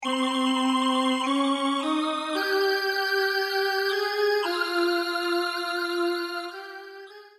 ROLAND D-50
Il offre ici des sonorités nouvelles basées sur une synthèse soustractive numérique et des attaques à base d'échantillions PCM.
Très puissant, très rock, le D-50 sera mis à grosse contribution dans l'album Révolutions comme l'a fait le Synthex dans Rendez-Vous.